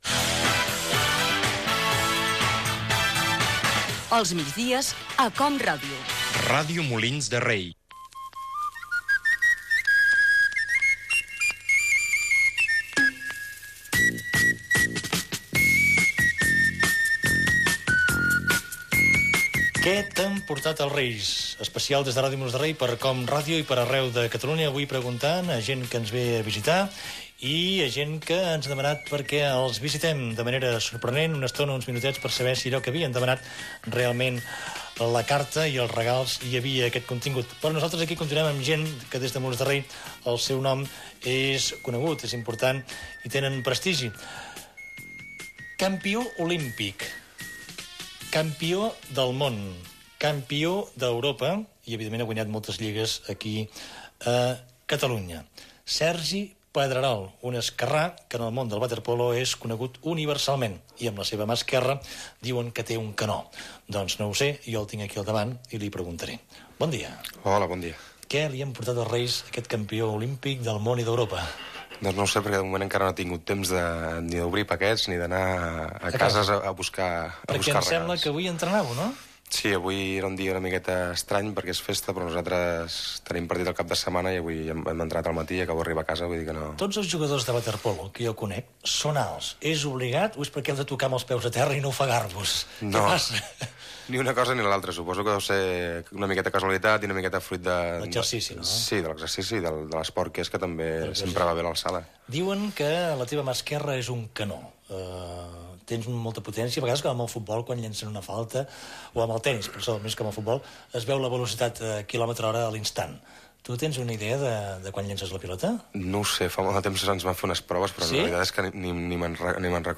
Programa especial "Què t'han portat els Reis?". Fragment d'una entreista al jugador de waterpolo Sergi Pedrerol.
Fragment extret de l'arxiu sonor de COM Ràdio.